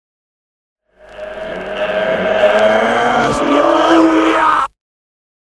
Genere: heavy metal
Incomprensibile